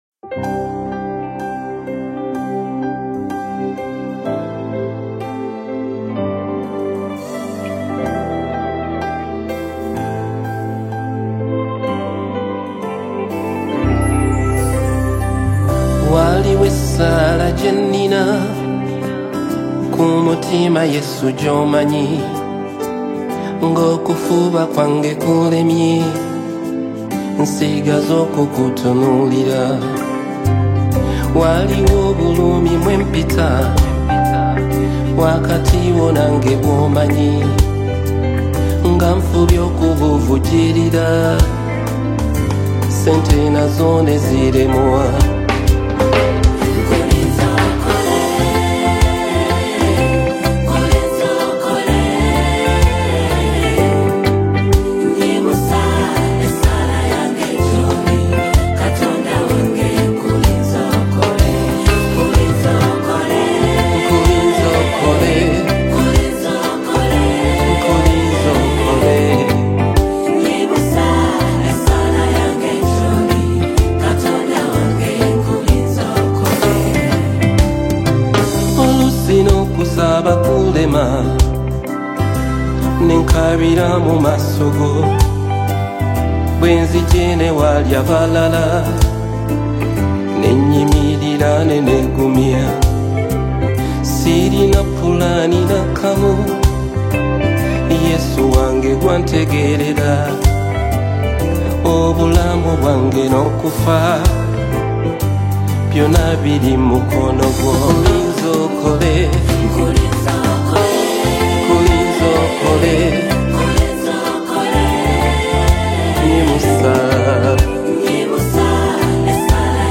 deeply moving worship song
strong voice and simple melody
soul-lifting gospel song